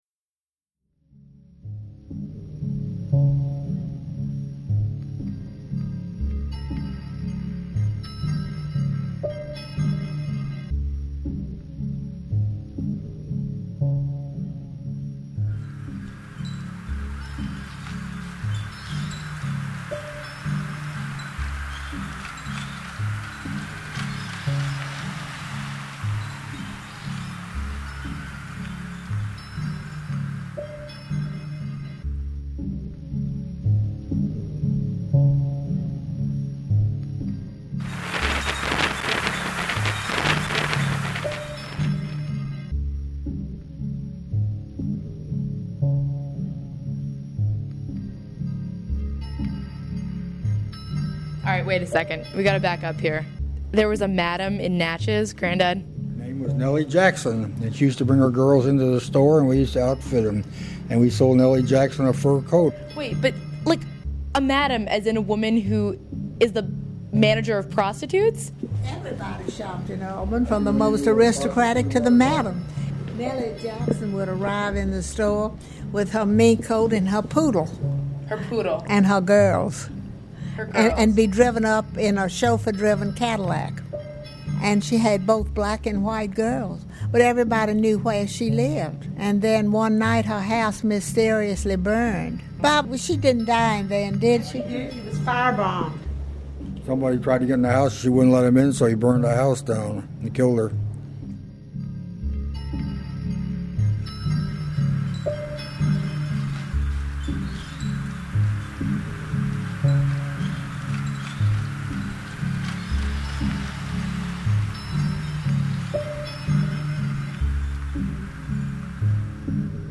The speakers here are actually members of my family – my mother and grandparents – discussing scandalous events that took place long ago in their Mississippi home town.